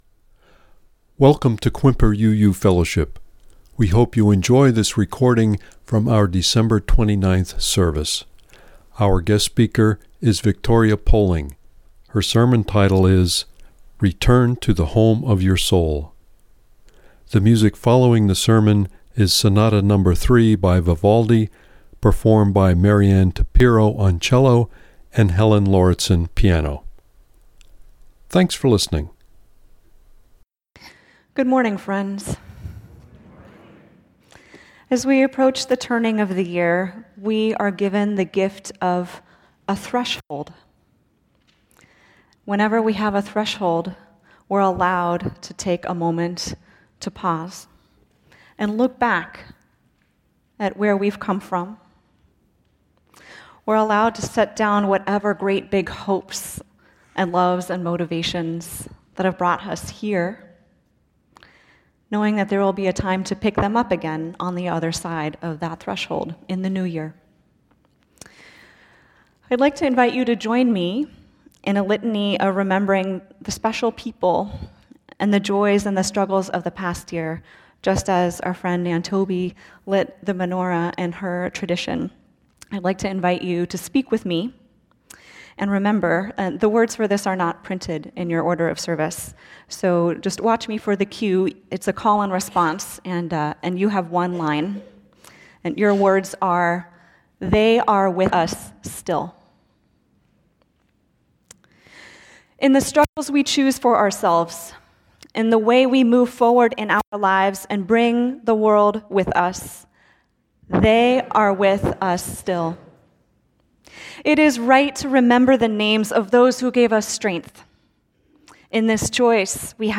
Click here to listen to the reading and sermon.